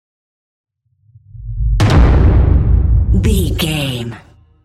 Dramatic hit explosion trailer
Sound Effects
Atonal
heavy
intense
aggressive